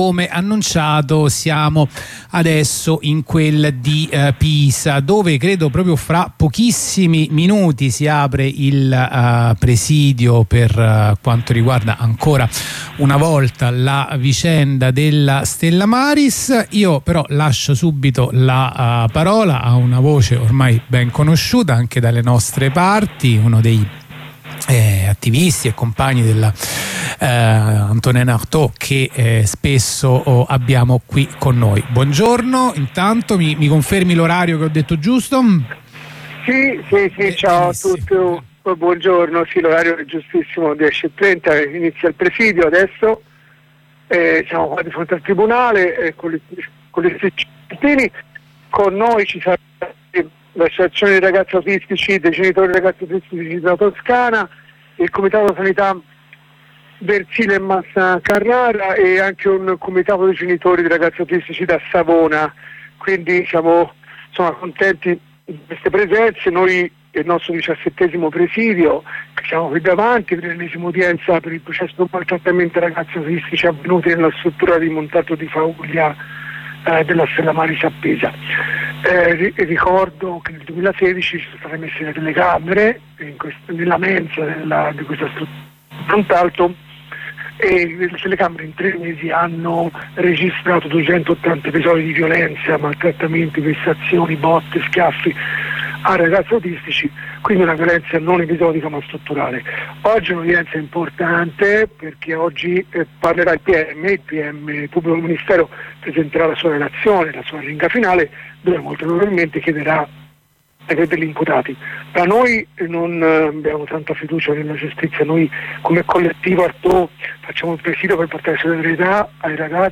Diciassettesimo presidio a Pisa per l'ennesima udienza riguardo la vicenda della Stella Maris, ci fornisce tutti gli aggiornamenti sul caso un compagno del collettivo Antonin Artaud.